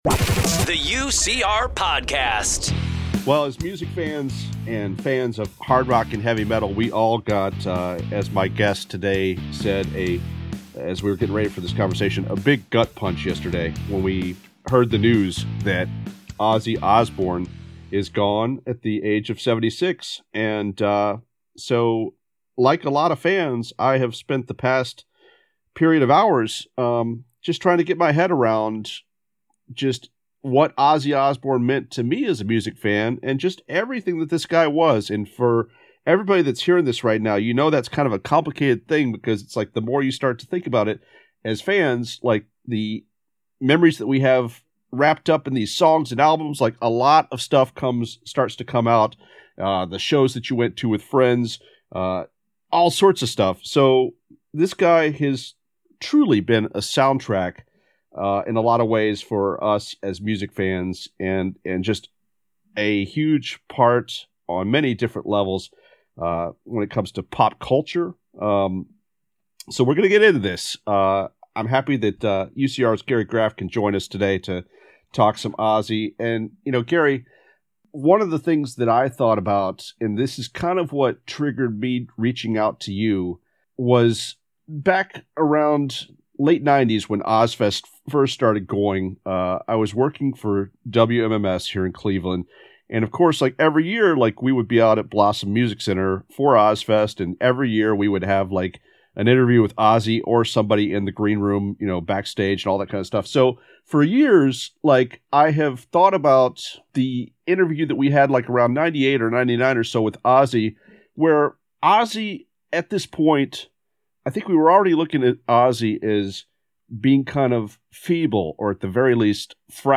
you'll stay up to date with the latest interviews, news, roundtable discussions and more.